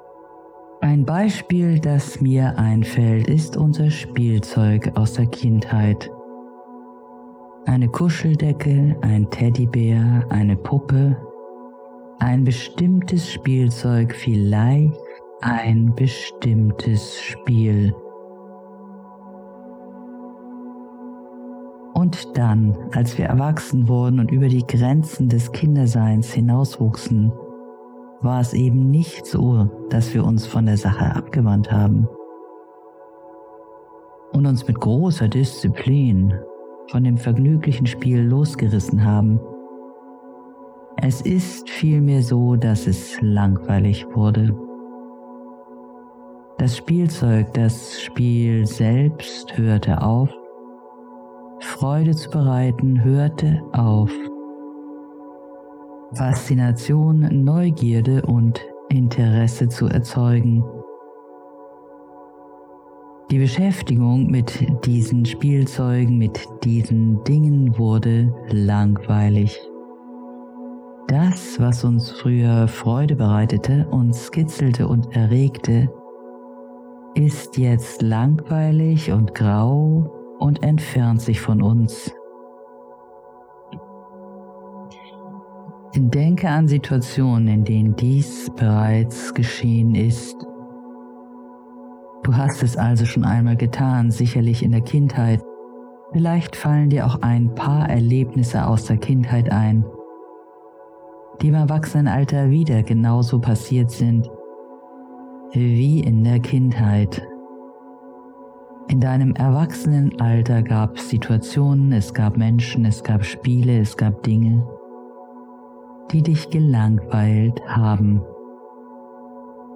• Musik: Ja;